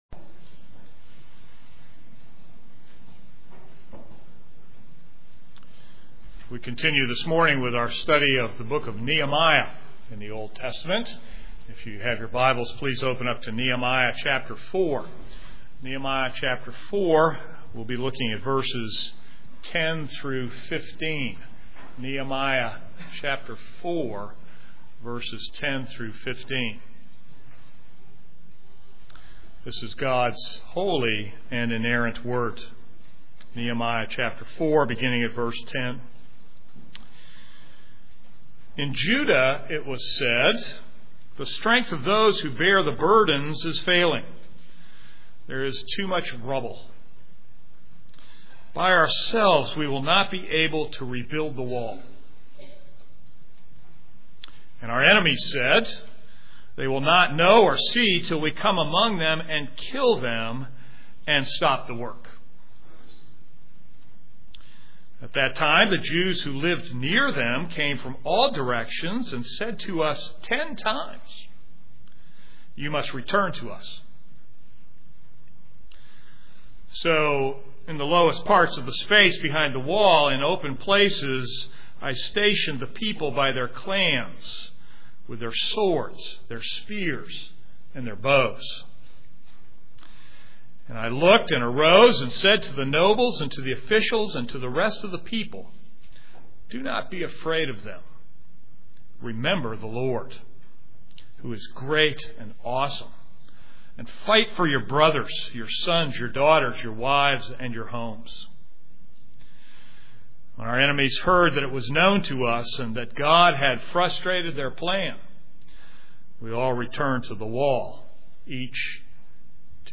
This is a sermon on Nehemiah 4:10-15.